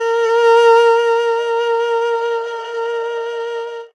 52-bi14-erhu-p-a#3.aif